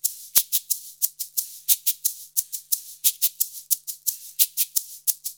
Index of /90_sSampleCDs/USB Soundscan vol.56 - Modern Percussion Loops [AKAI] 1CD/Partition A/04-FREEST089